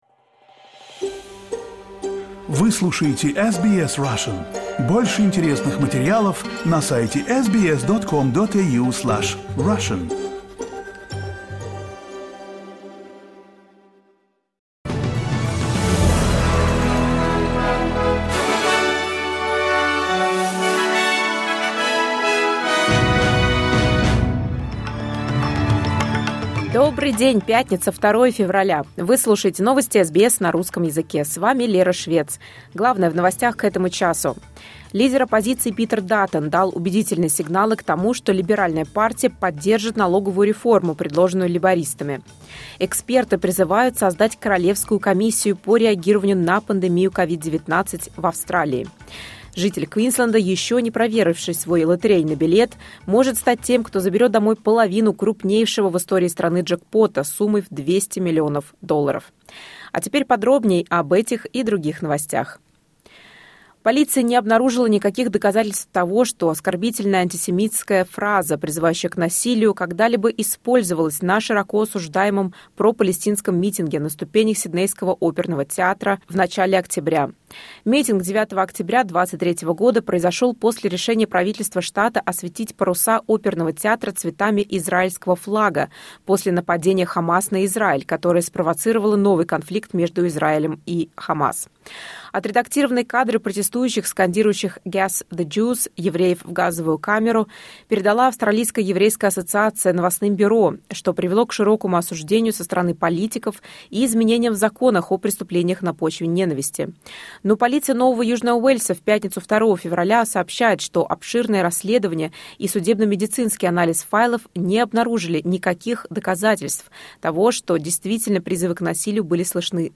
SBS news in Russian — 02.02.2024